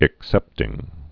(ĭk-sĕptĭng)